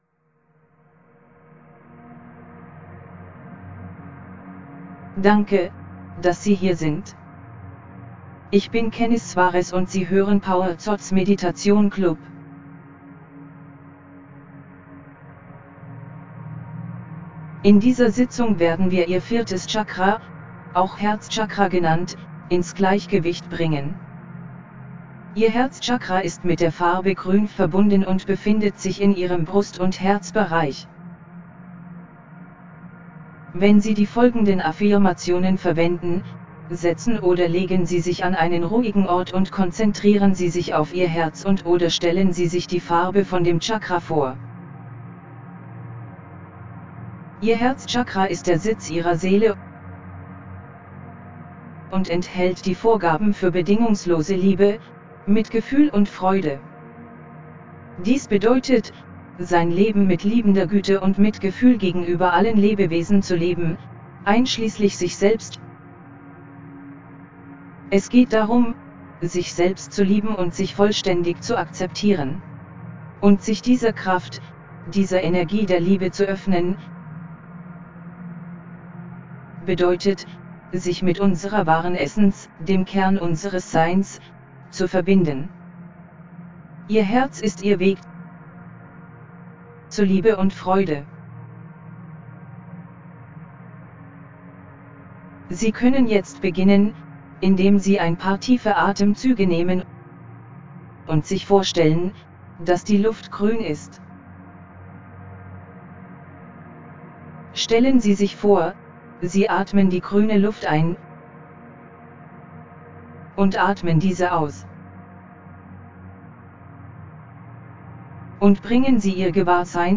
4ActivatingQiFlowOfHeartChakraMeditationDE.mp3